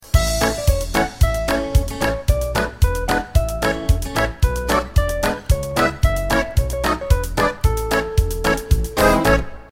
Listen to a sample of the instrumental version.
Downloadable Instrumental Track